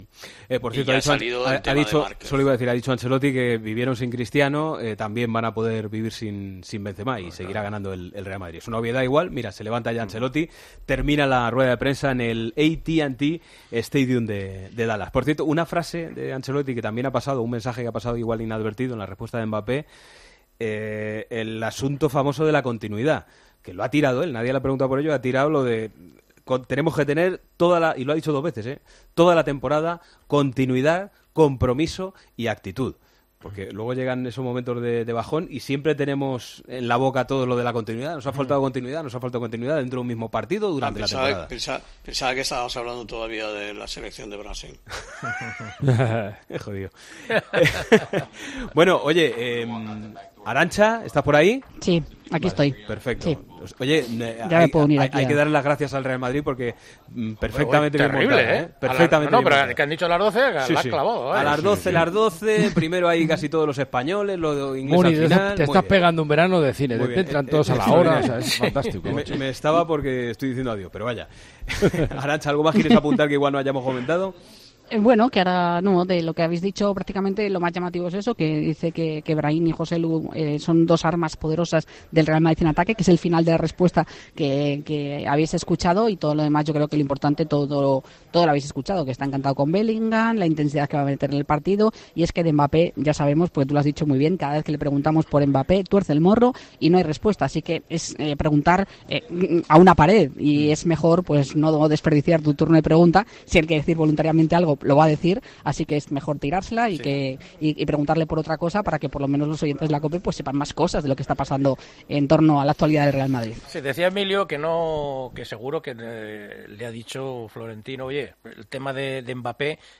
Una de las cosas de las que también se habló en la tertulia de El Partidazo de COPE es sobre ese conflicto que Mbappé sigue teniendo con el PSG.